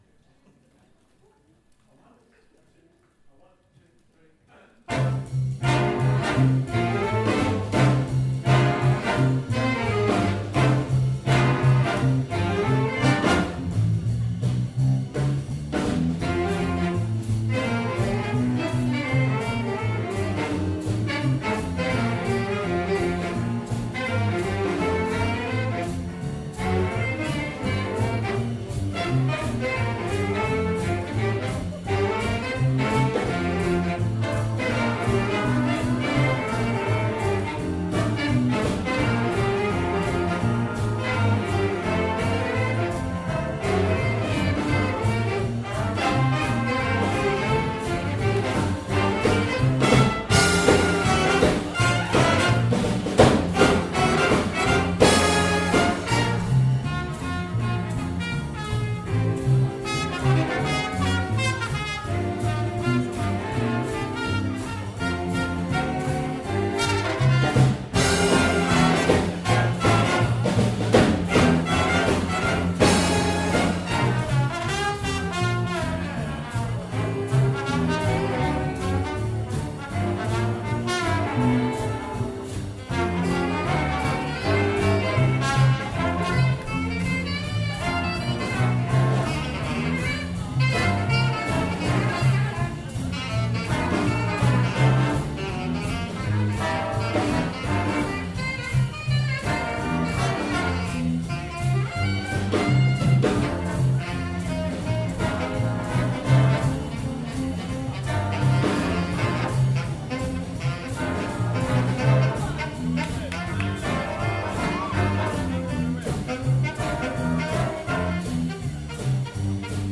From the Big Band Evening March 2017